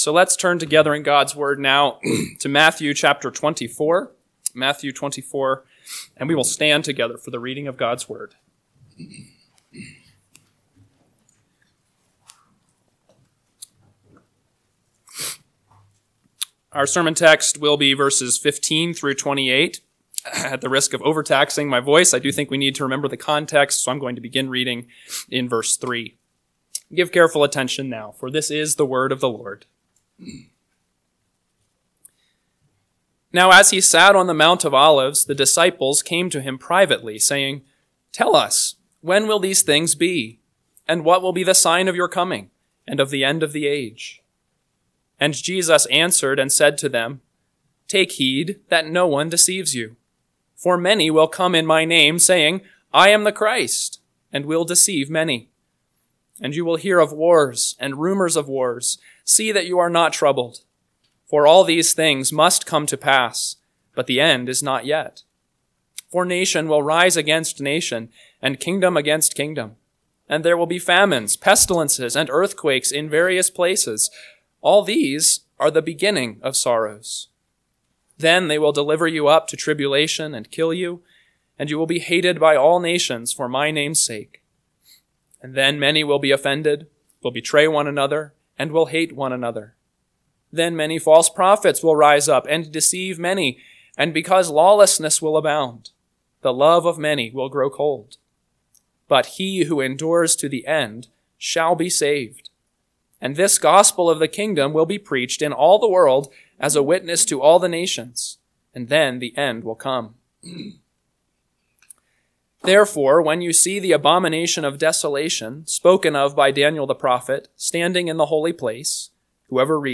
AM Sermon – 12/15/2024 – Matthew 24:15-28 – Northwoods Sermons